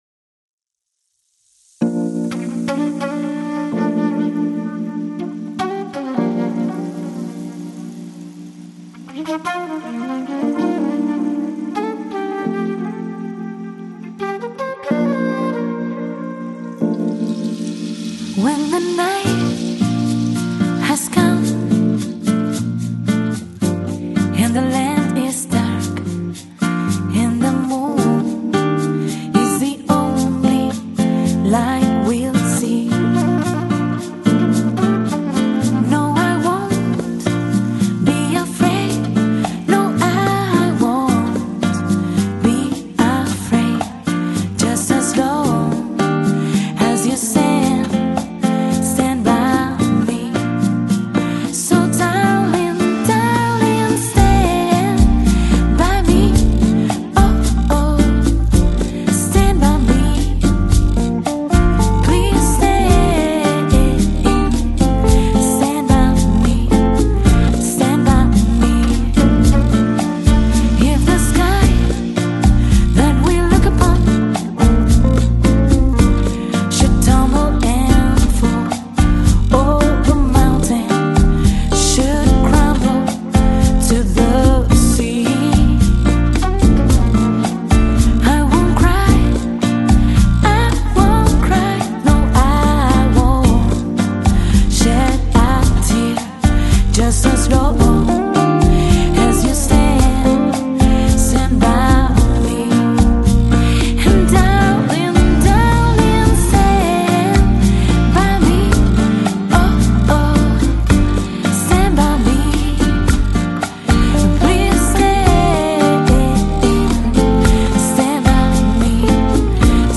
FLAC Жанр: Downtempo, Lounge, Jazz Носитель